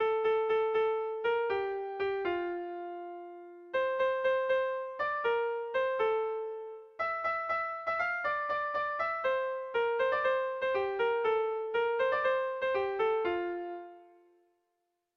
Kontakizunezkoa
Kopla handia
ABD